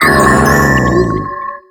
Cri d'Archéodong dans Pokémon X et Y.